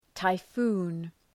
Προφορά
{taı’fu:n}